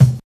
99 KICK 2.wav